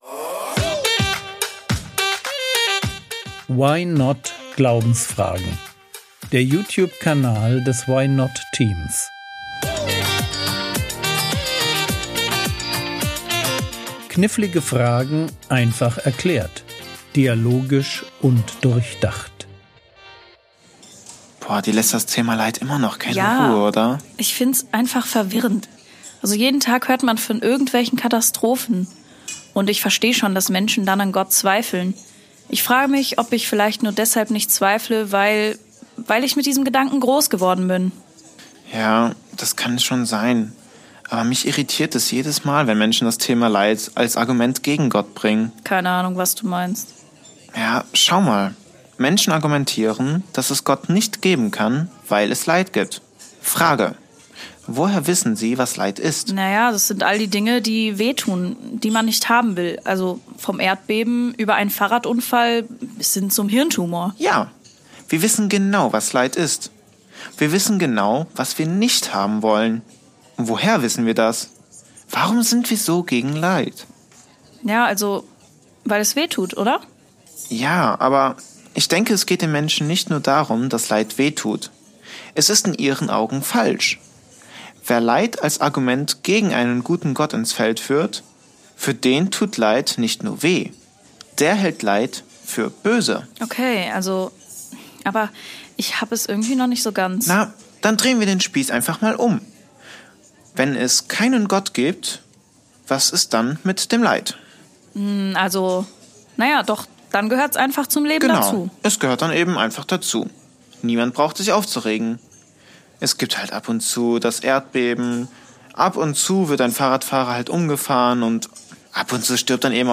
Warum fühlt sich Leid falsch an? ~ Frogwords Mini-Predigt Podcast